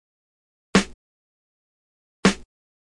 嘻哈五人组" 鼓的插入2
描述：这就是Hip Hop的5个包。我们建立了5个完整的循环，并将它们全部分解到每个乐器，还包括每个完整的混音，供你的混音项目使用。
标签： 160 啤酒花 5 嘻哈 looppacks BPM 玉米
声道立体声